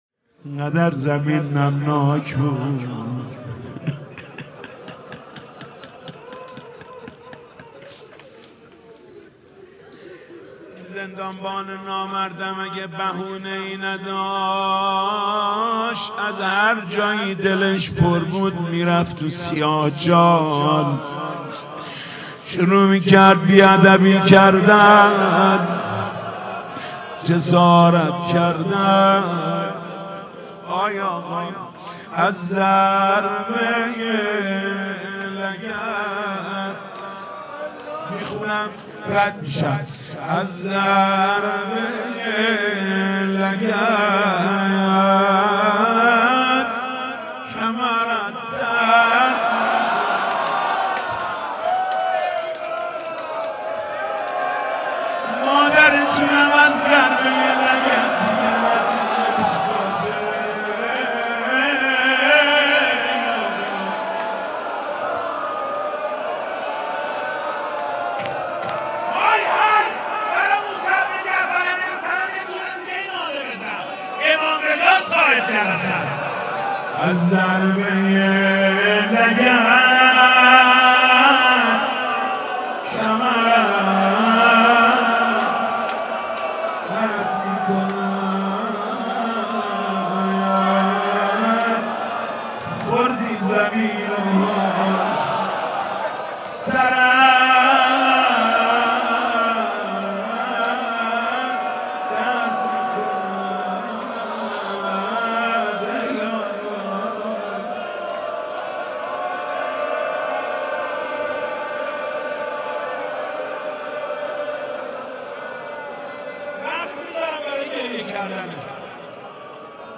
با مداحی حاج محمود کریمی برگزار شد